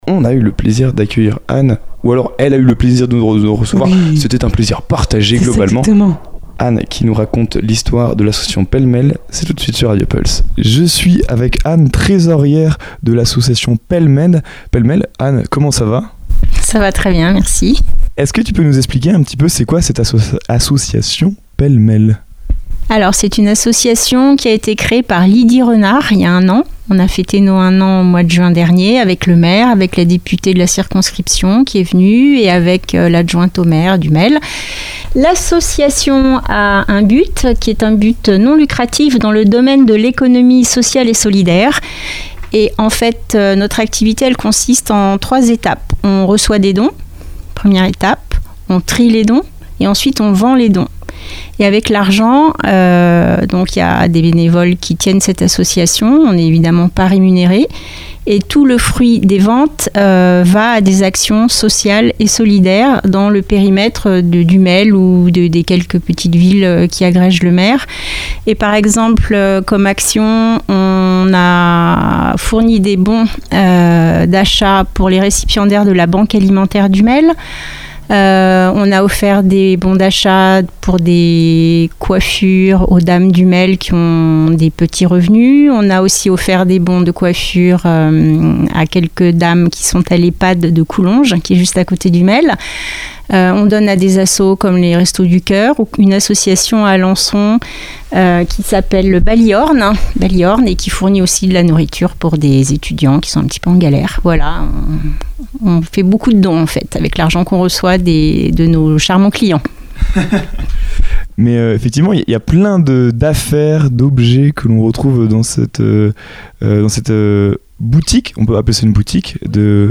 Une interview inspirante et engagée, entre écologie, créativité et dynamisme local.